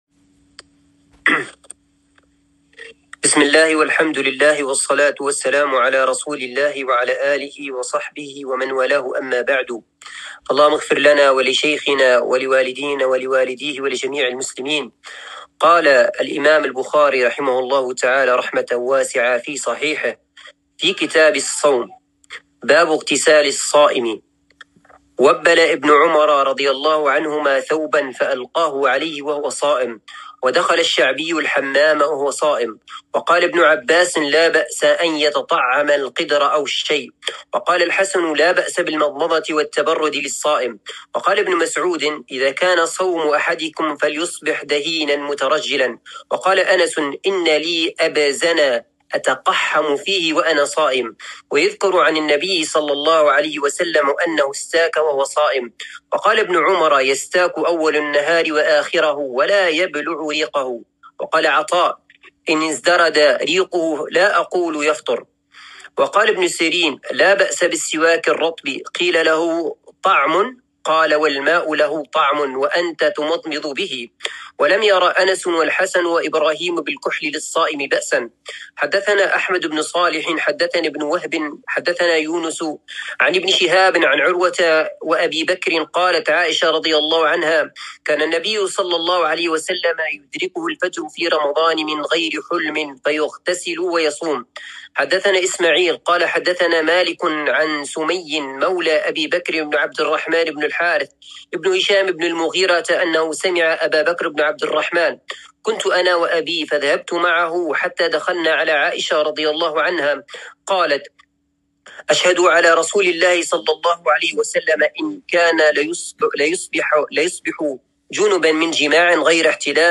الدرس الخامس من شرح كتاب الصيام من صحيح البخاري